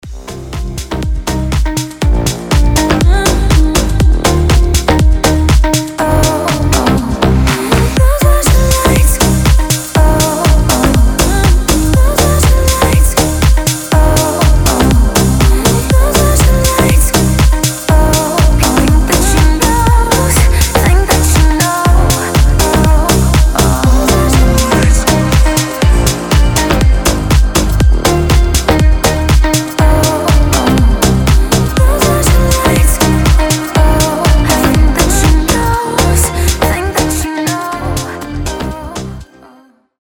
deep house
женский голос
чувственные
Классная музыка в стиле deep house